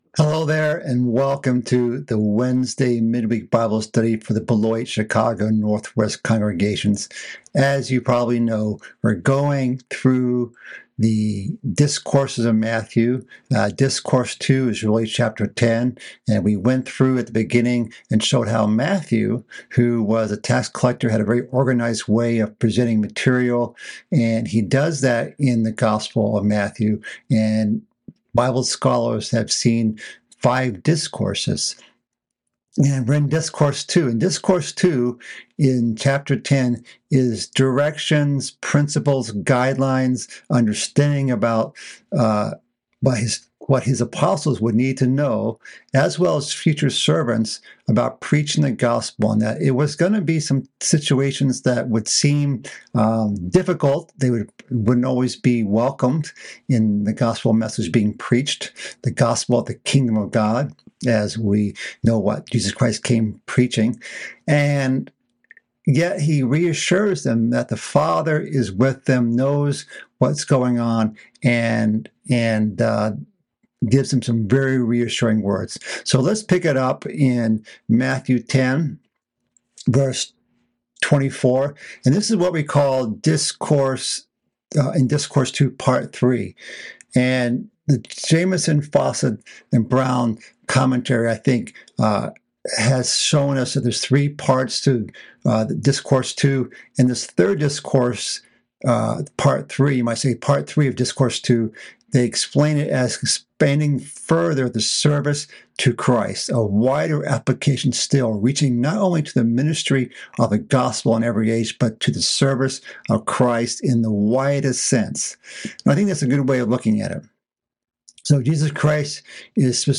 This is the fourth part of a mid-week Bible study series covering Christ's second discourse in the book of Matthew. This message continues in chapter 10 of Matthew, covering the topic of not being fearful.